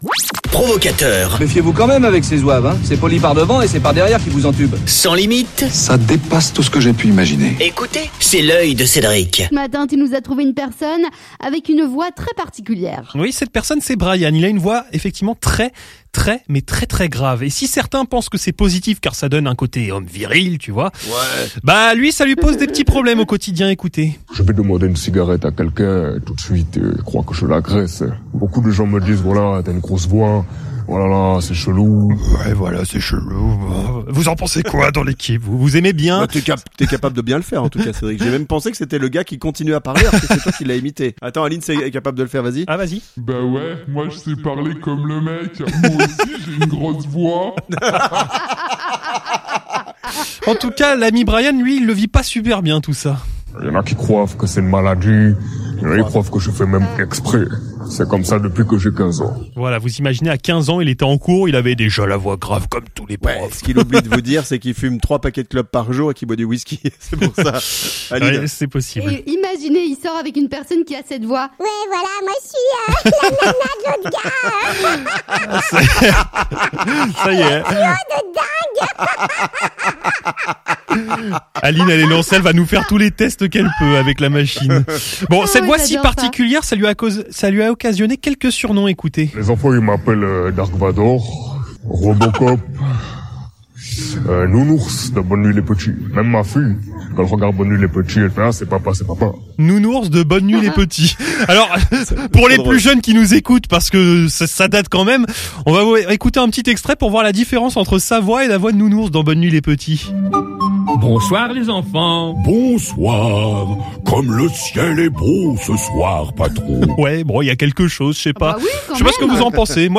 Une voix très particulière